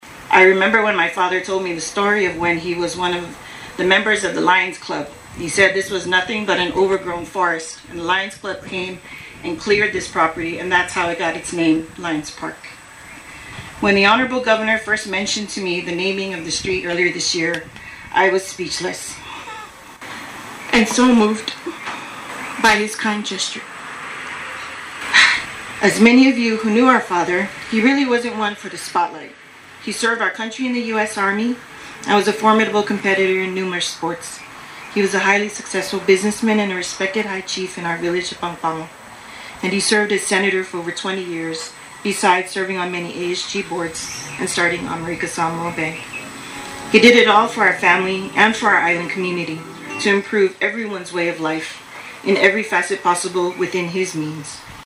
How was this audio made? A ground breaking ceremony took place yesterday at the site where the Samoa Motors Ford and Pago Motors Hyundai auto dealerships will build showrooms, offices and auto shops.